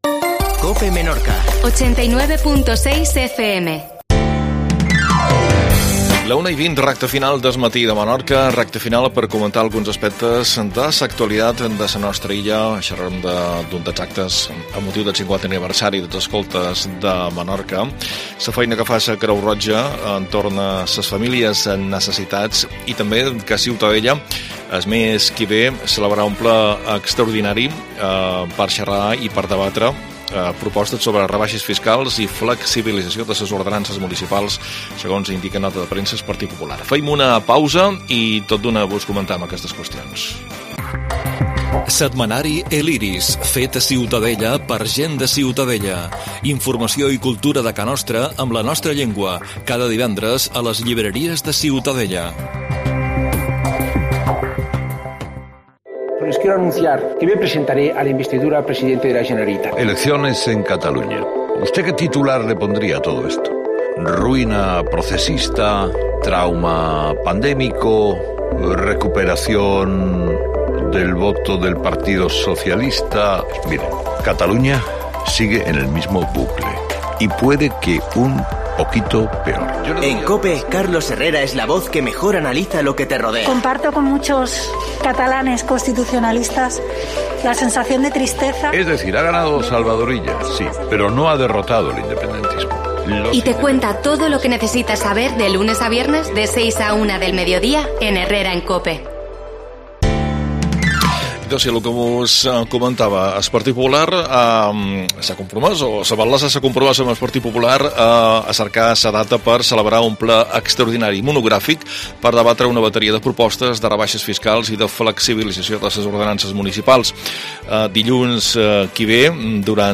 AUDIO: Noticies. Maó no comprara el solar del carrer Vasallo